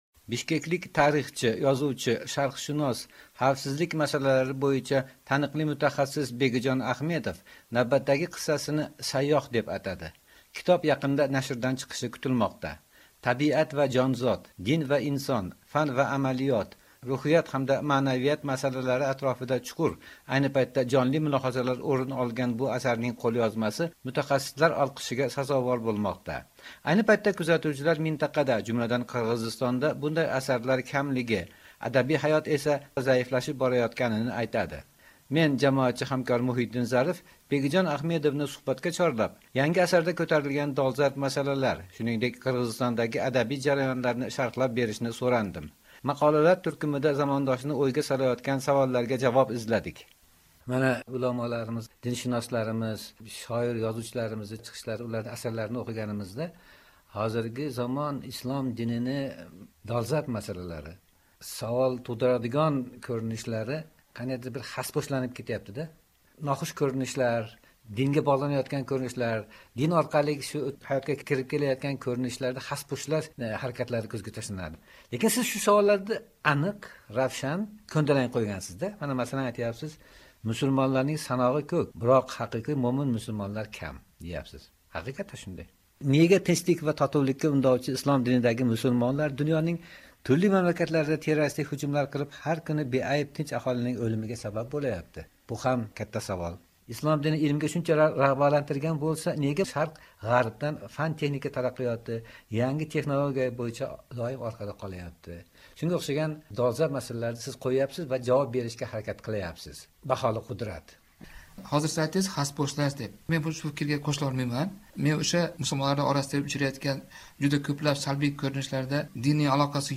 suhbat (2-qism)